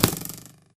bowhit1.ogg